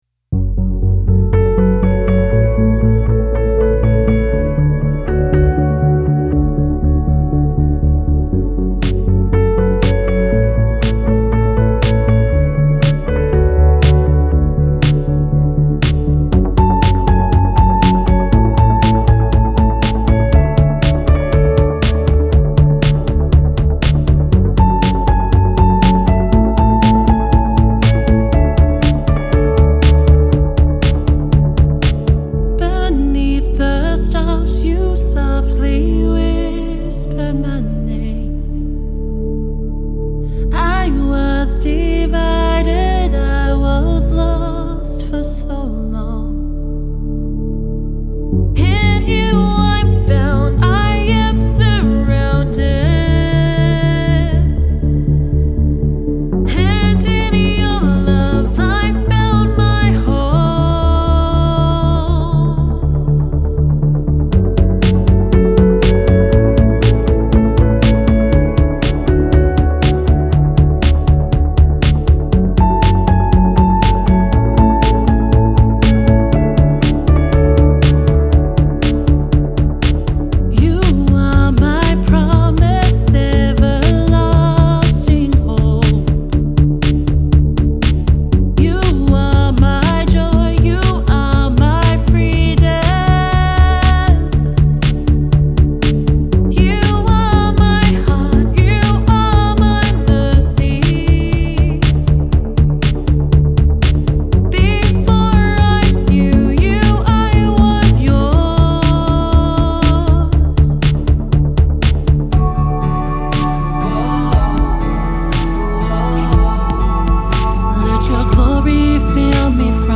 fun song